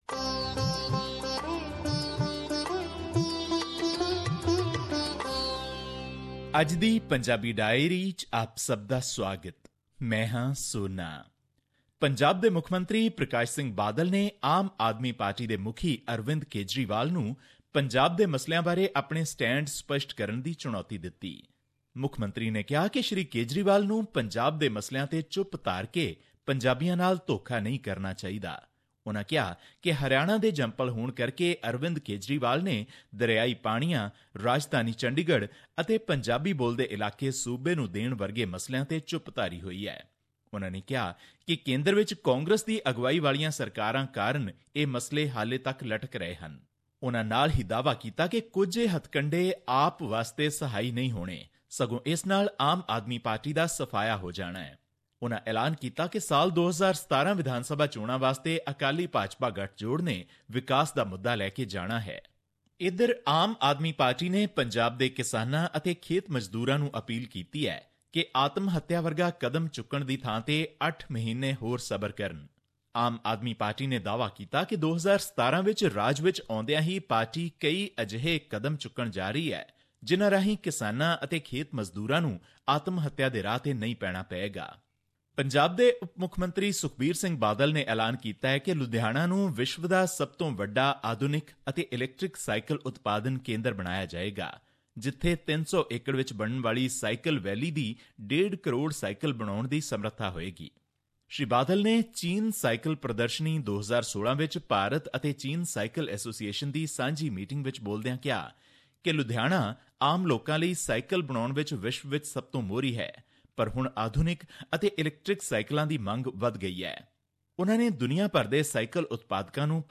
Here's the podcast in case you missed hearing it on the radio.